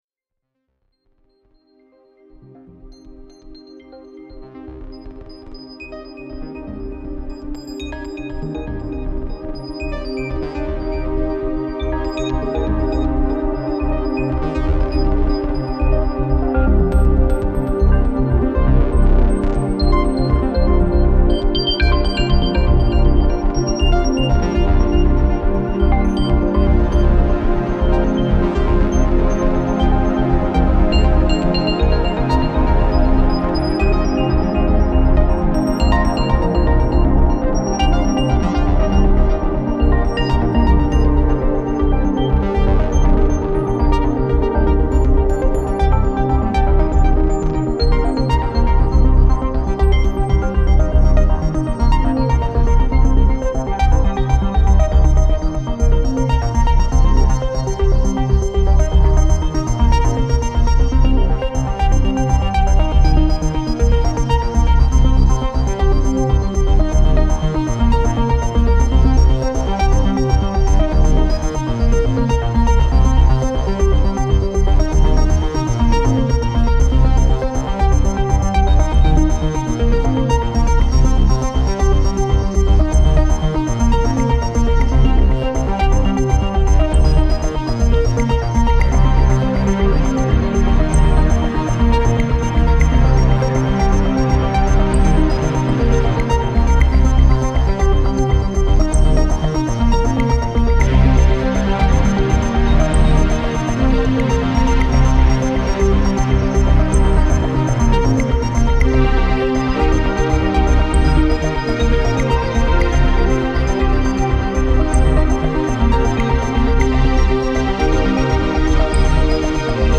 Genre: electronica, electronic, synthwave.